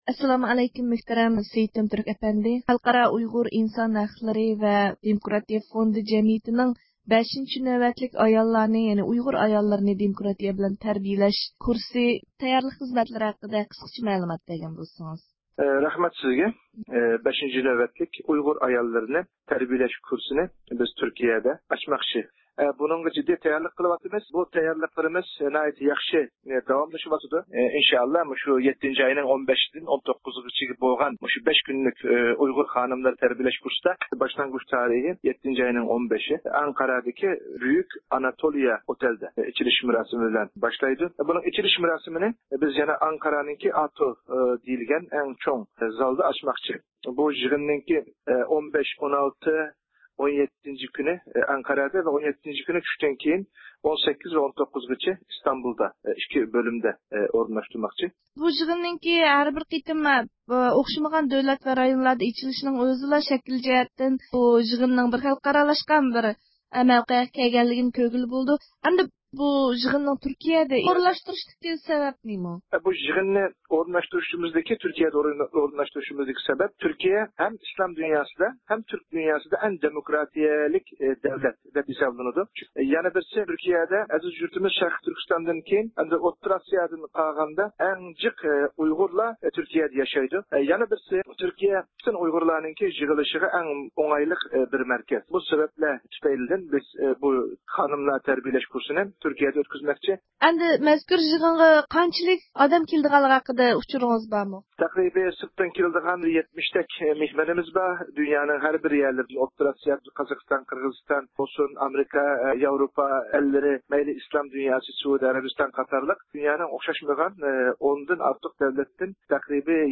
مۇخبىرىمىزنىڭ ئۆتكۈزگەن سۆھبىتىدىن ئاڭلايلى.